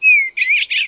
animalia_eastern_blue_3.ogg